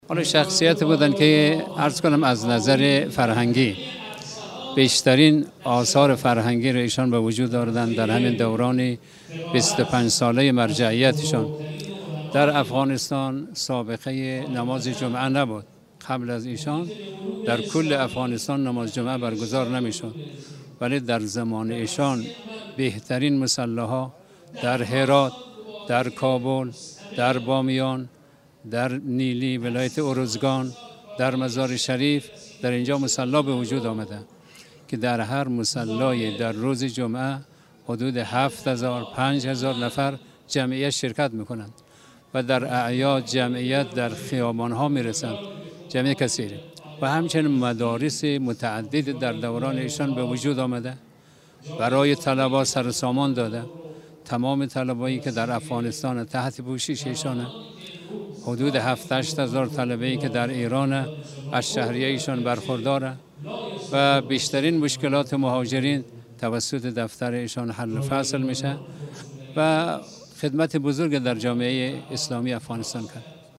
در گفت‌وگو با خبرنگار خبرگزاري رسا